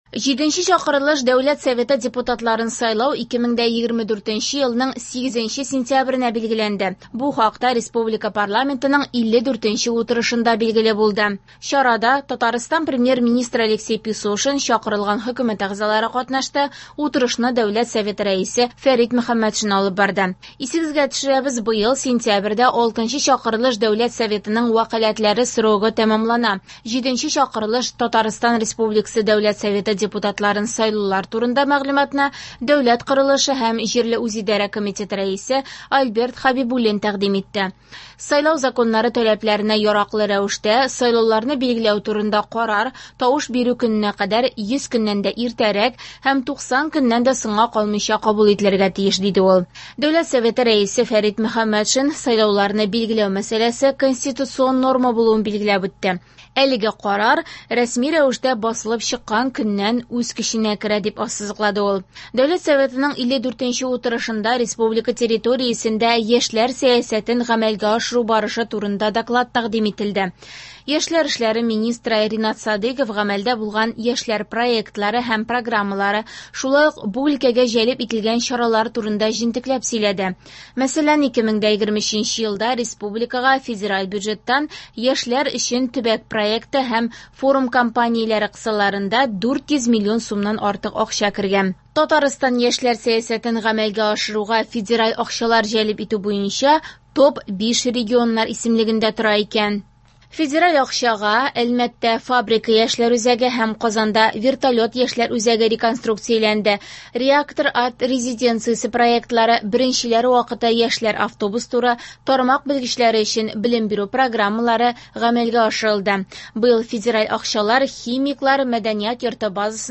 Радиоотчет (06.06.24) | Вести Татарстан